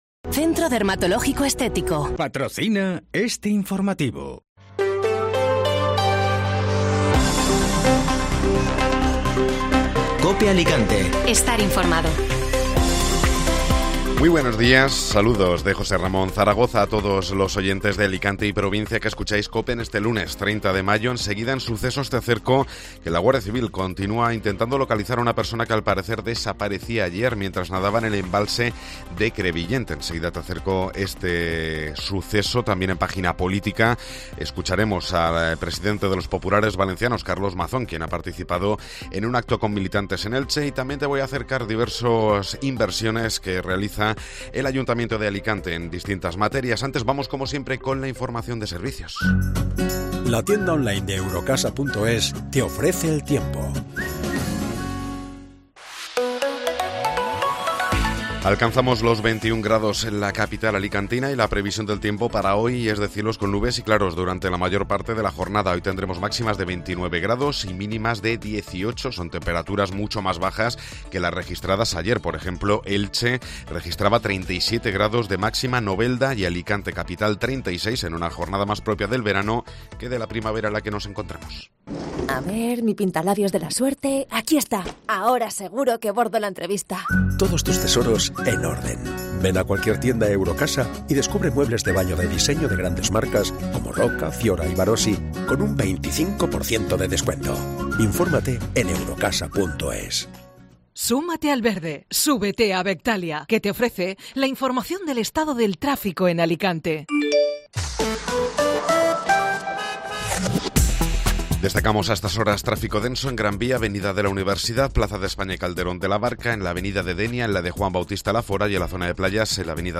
Informativo Matinal (Lunes 30 de Mayo)